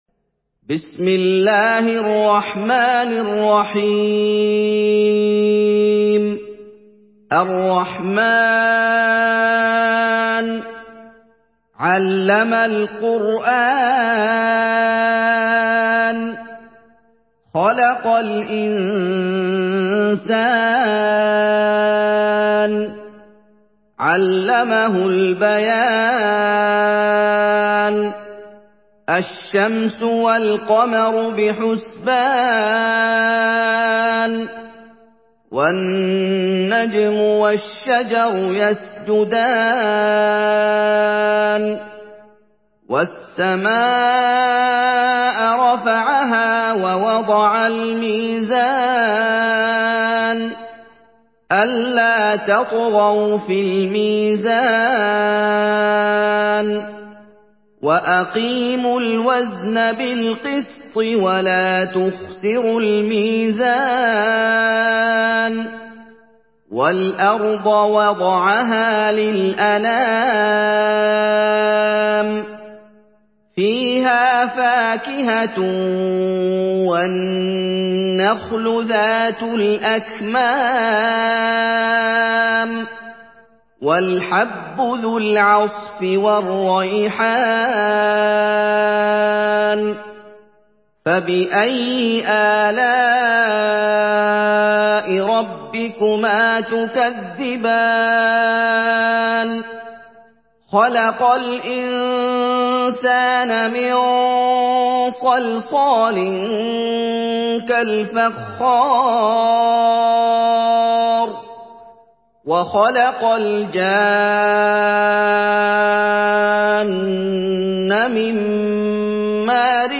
Surah Rahman MP3 by Muhammad Ayoub in Hafs An Asim narration.
Murattal